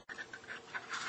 All Saints Sermons